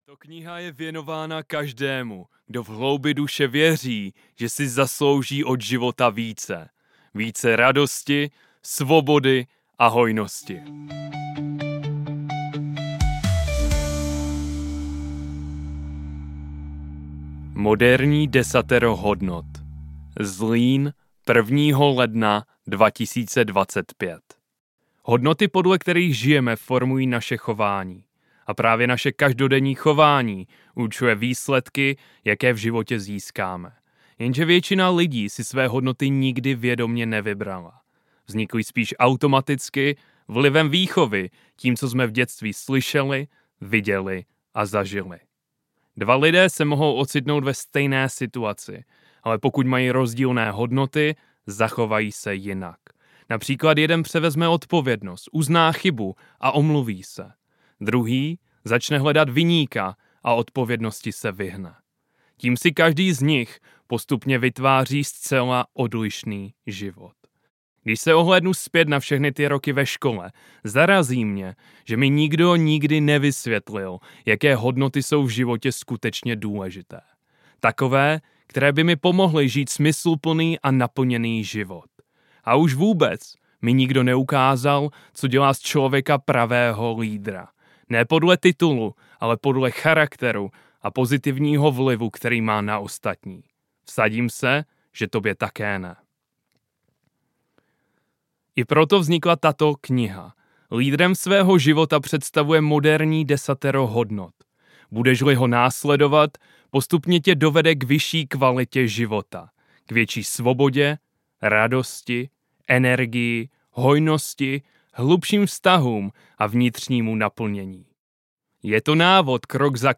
Lídrem svého života audiokniha
Ukázka z knihy